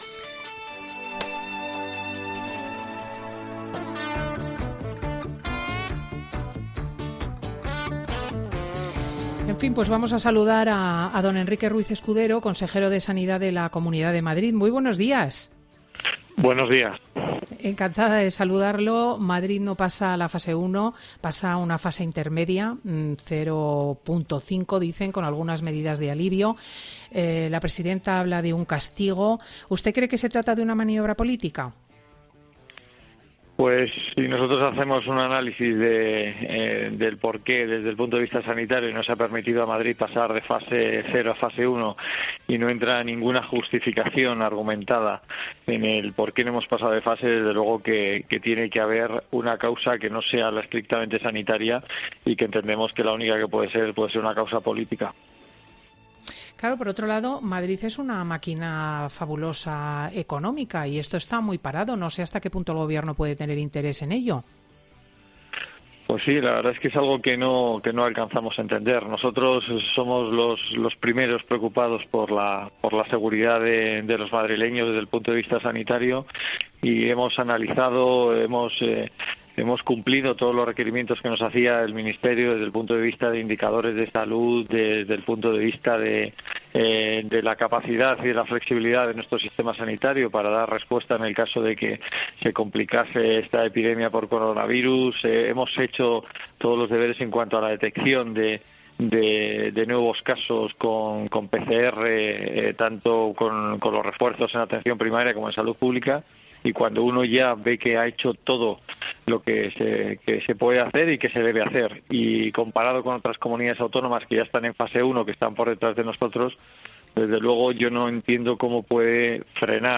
“ El castigo a Madrid siempre se da de manera implacable ”, explica el consejero en Fin de Semana de COPE.